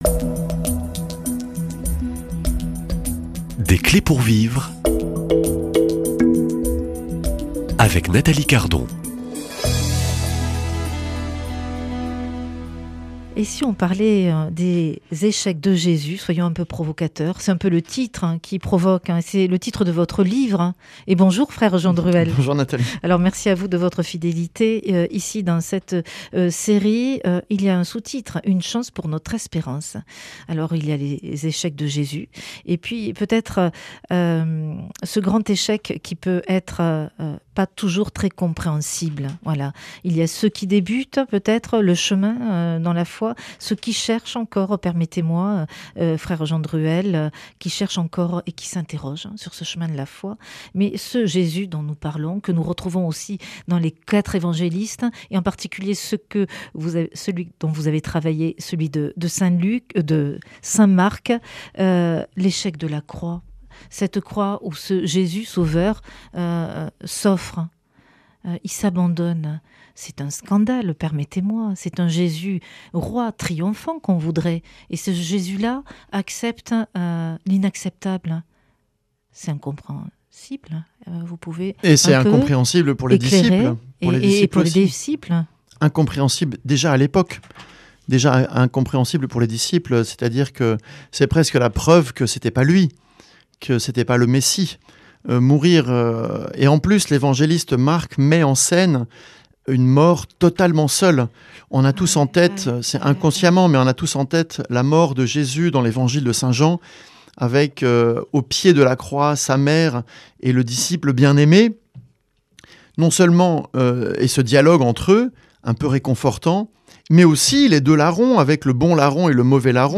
Jésus annonce sa passion, mais ses disciples refusent d’entendre. Dans cet épisode, je dialogue